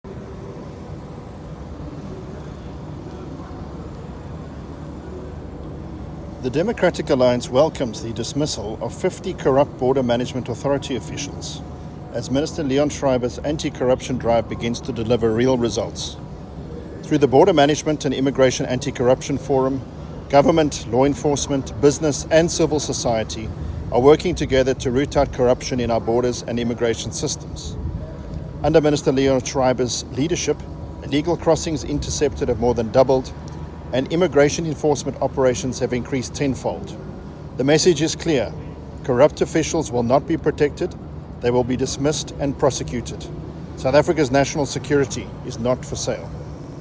English soundbite by Adrian Roos MP.